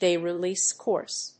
アクセントdáy relèase cóurse